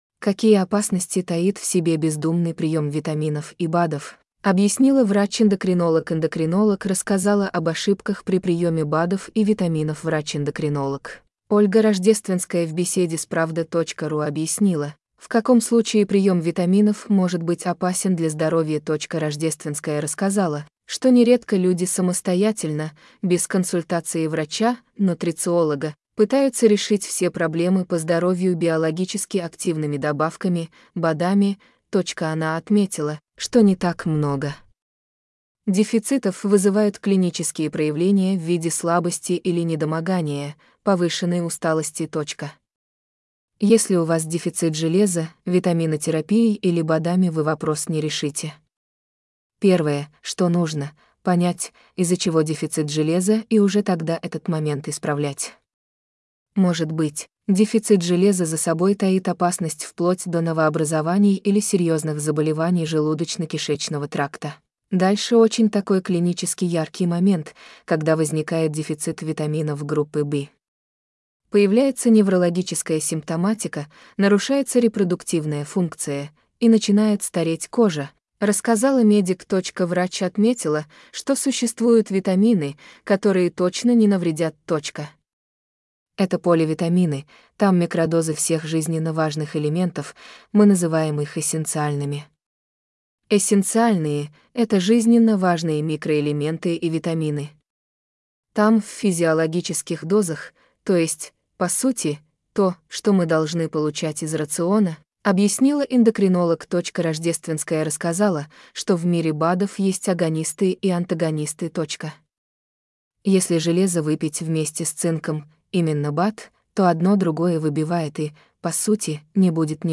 скачать интервью в docx формате